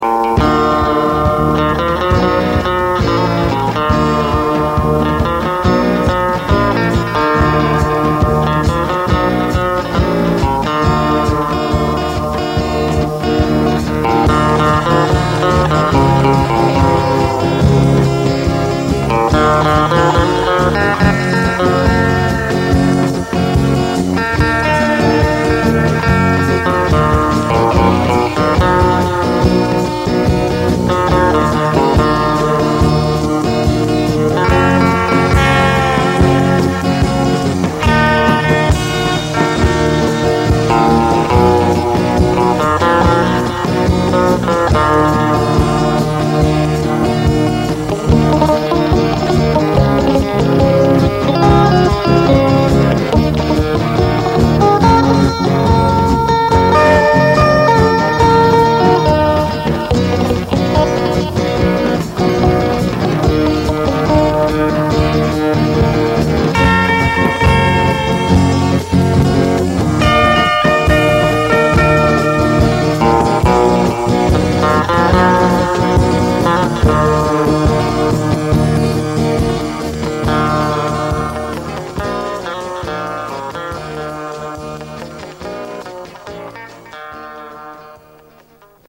Äänitetty treenikämpällä 1999